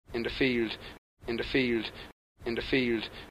Alveolar /l/ with local Carlow speaker
Carlow_Alveolar-L.mp3